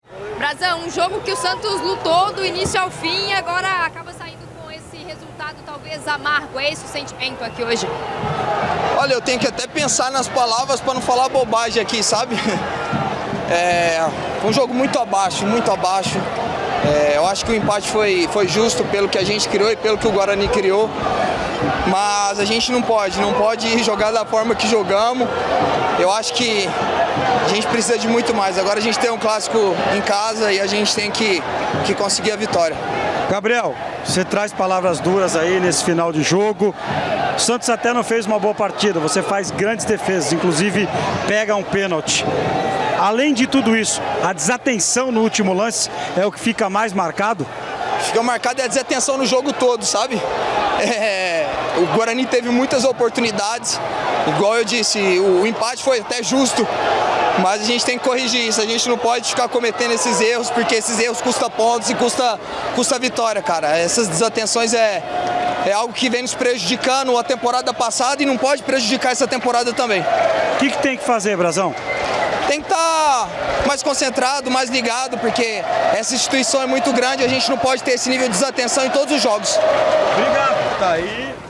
APÓS A PARTIDA GABRIEL BRAZÃO INCONFORMADO APONTA CULPADOS E EXPÕE ERROS GRAVES NO EMPATE ENTRE SANTOS E GUARANI
TENHO-QUE-PENSAR-NAS-PALAVRAS-PRA-NAO-FALAR-BOBAGEM-BRAZAO-E-DURO-DEPOIS-DO-EMPATE-DO-SANTOS.mp3